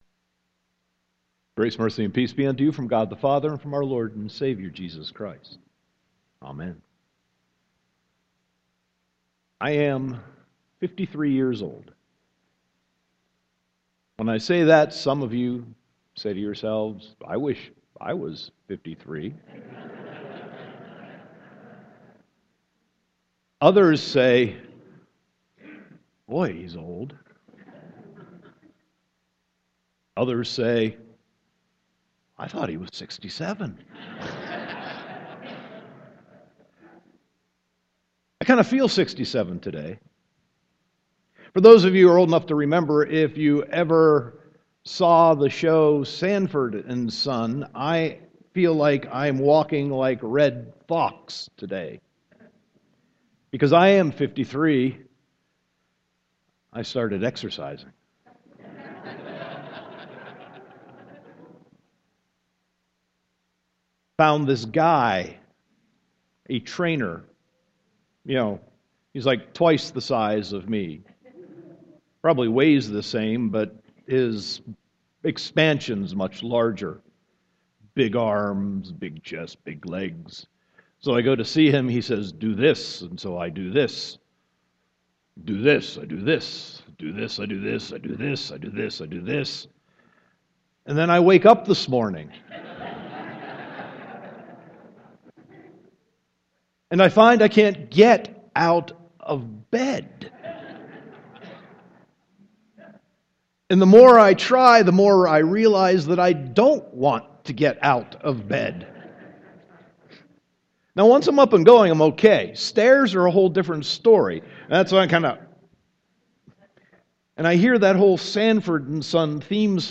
Sermon 2.8.2015